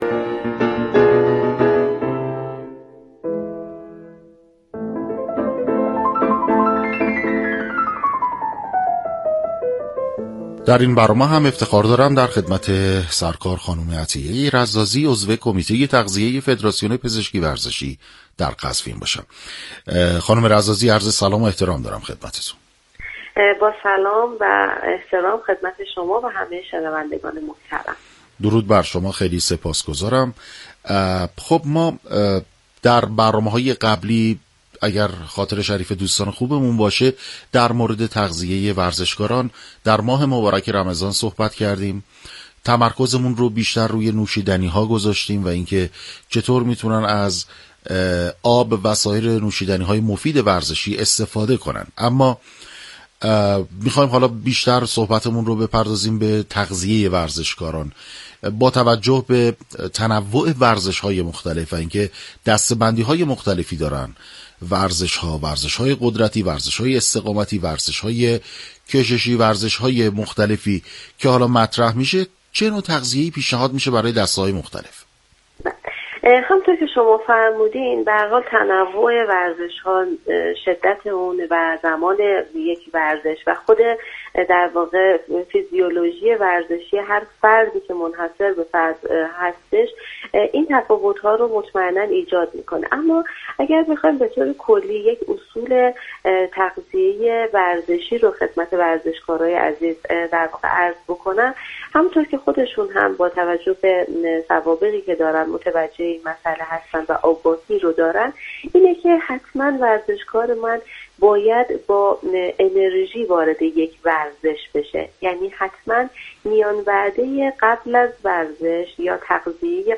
در گفت و گو با برنامه نسخه ورزشی رادیو ورزش